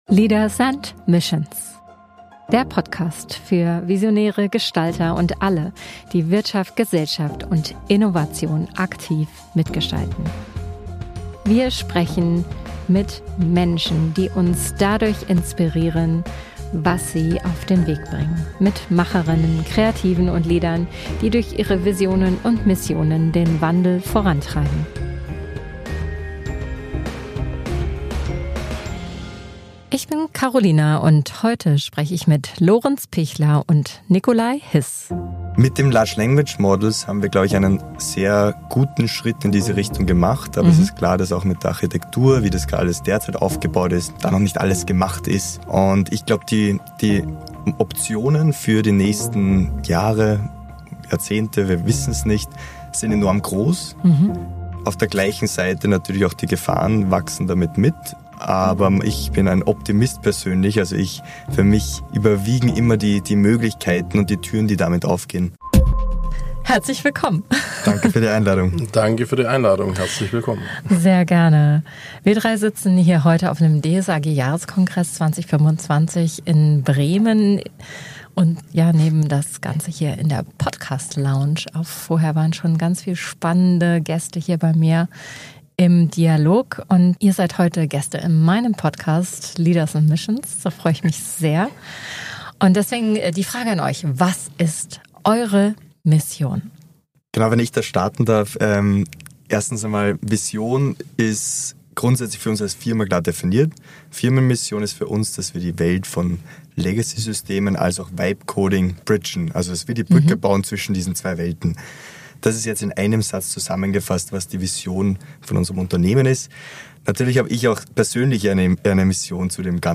Ein Gespräch über technische Tiefe, menschliche Motivation und eine klare Vision: Wissen zurückbringen, Innovation ermöglichen.